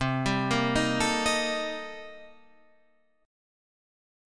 Cm13 chord